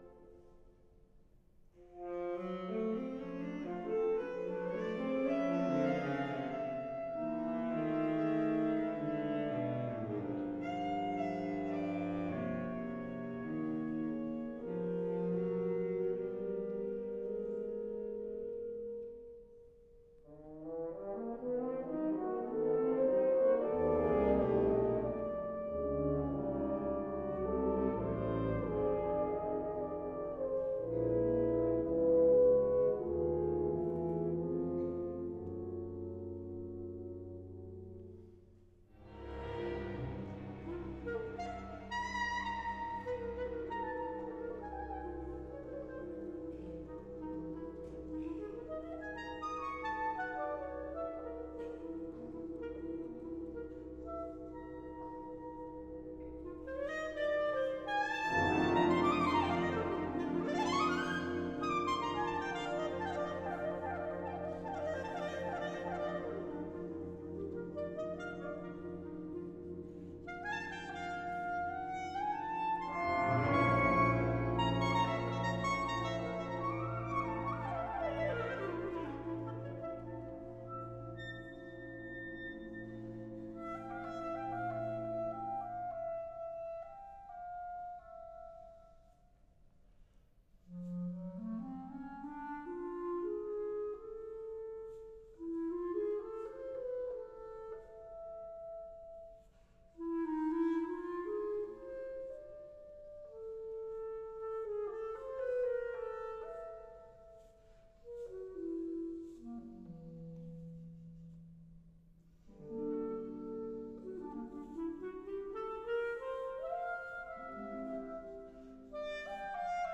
Please note: These samples are of varying quality.
Most were taken from live performances and are intended
for Clarinet and Orchestra